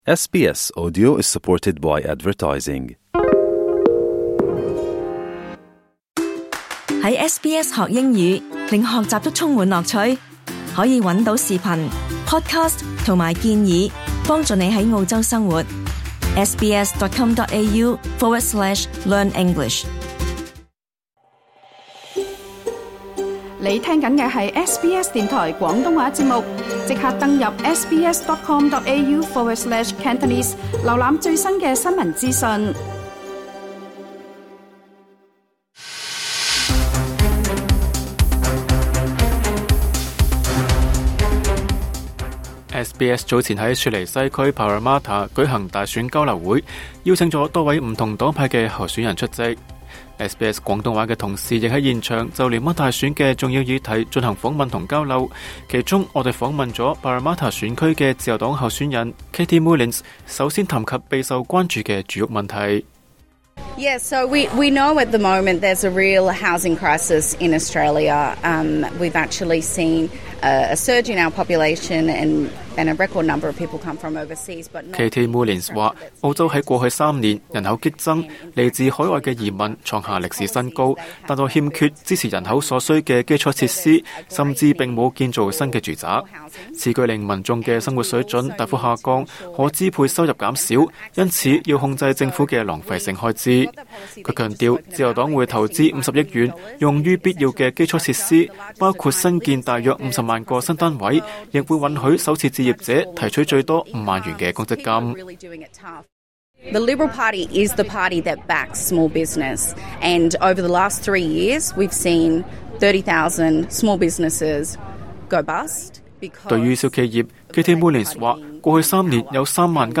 聯邦大選在即，SBS早前在雪梨西區Parramatta舉行大選交流會，邀請了多位不同黨派的候選人，以及社區人士出席，就聯邦大選的重要議題進行訪問和交流。